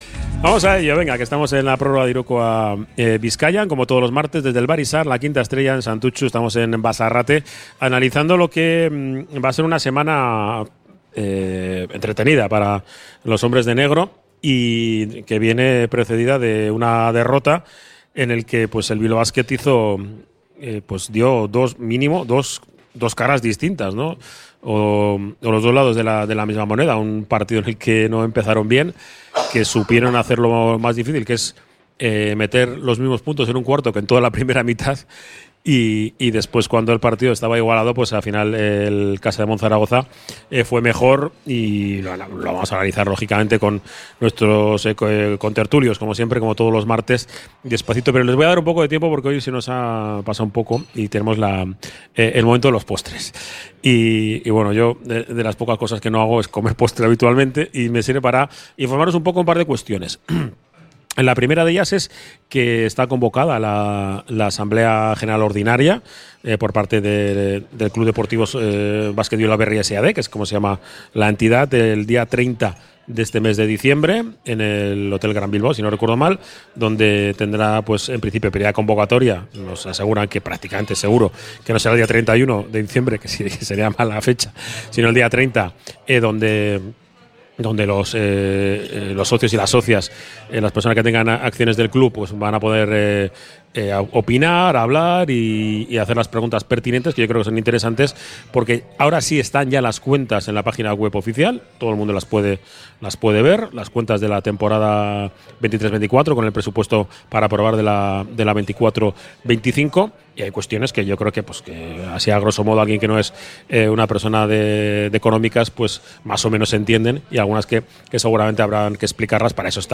Desde el Bar Izar la Quinta Estrella de Santutxu